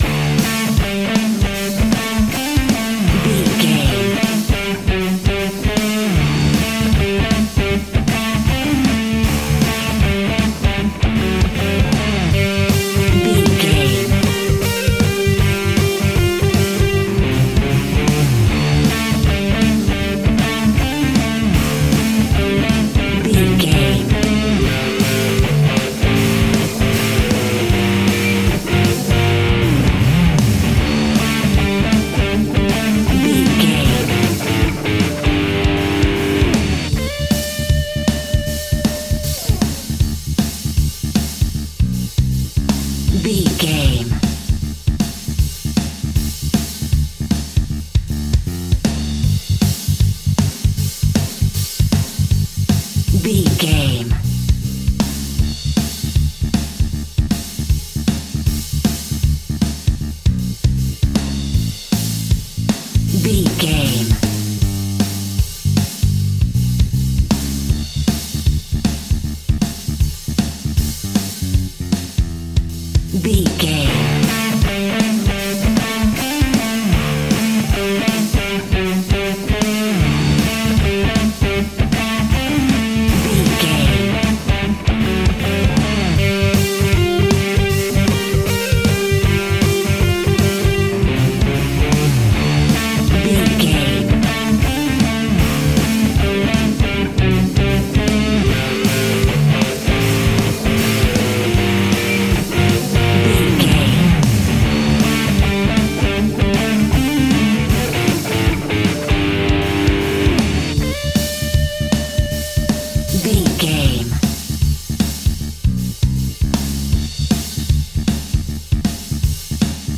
Aeolian/Minor
D
hard rock
blues rock
distortion
Rock Bass
heavy drums
distorted guitars
hammond organ